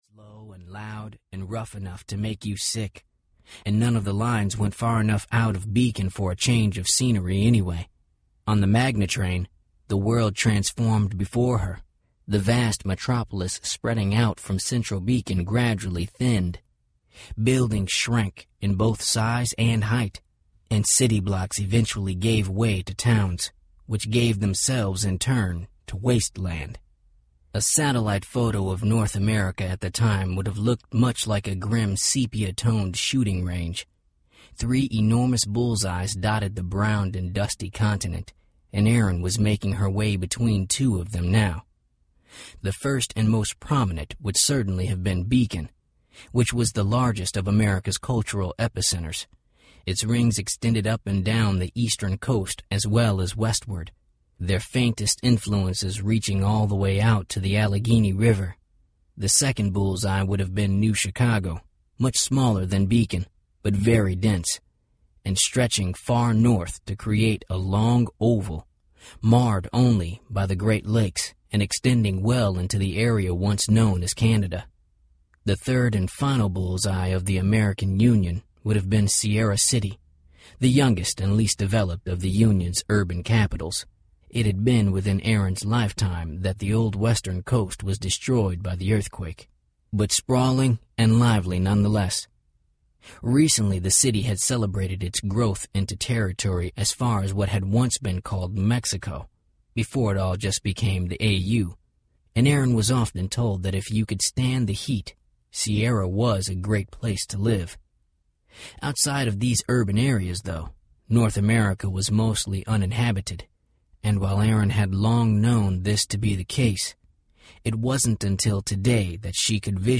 Swipe (Swipe Series, Book #1) Audiobook
8 Hrs. – Unabridged